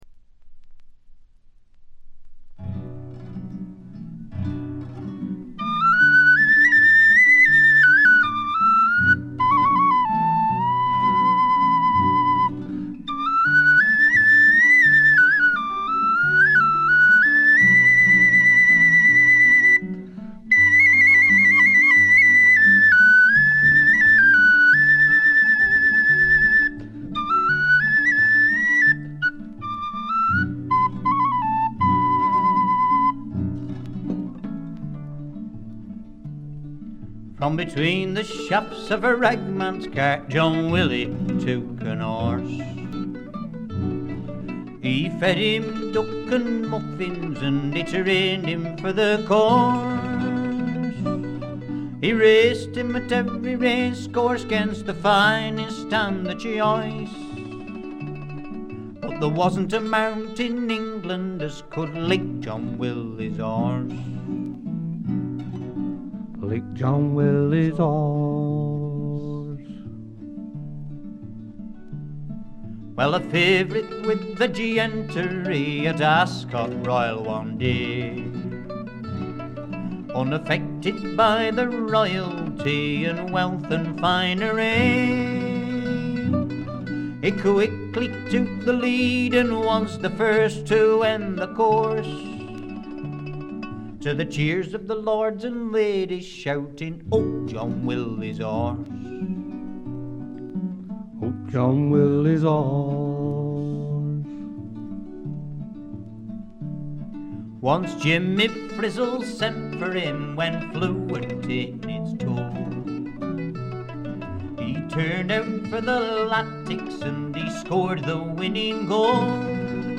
部分試聴ですがほとんどノイズ感無し。
試聴曲は現品からの取り込み音源です。
banjo
guitar
vocals, chorus, mandolin, whistle